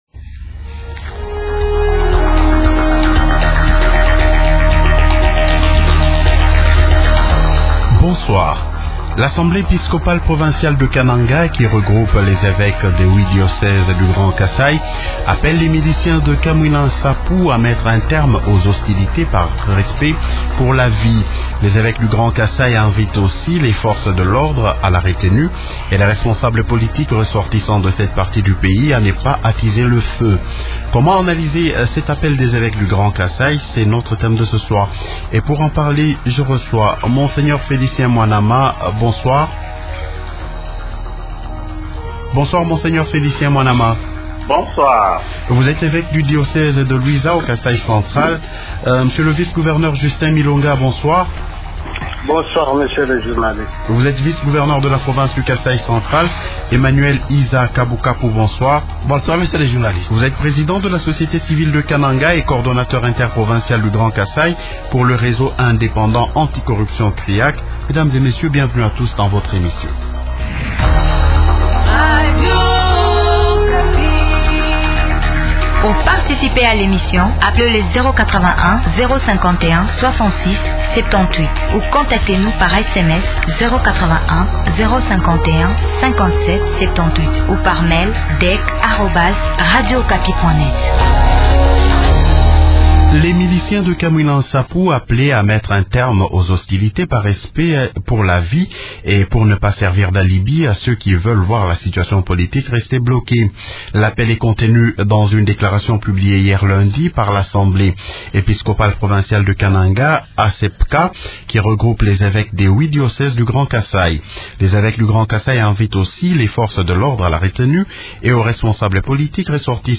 -Comment analyser cet appel des évêques ? Invités -Justin Milonga, Vice-gouverneur de la Province du Kasai Central. -Monseigneur Félicien Mwanama, Evêque du diocèse de Luisa au Kasai central.